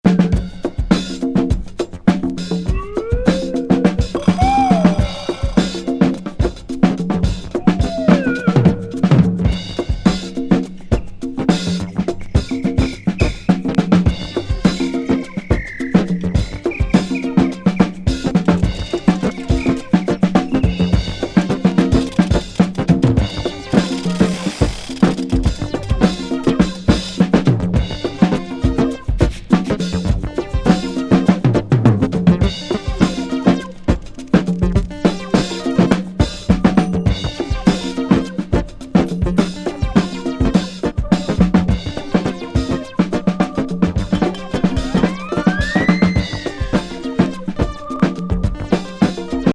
コズミック・グルーヴの嵐。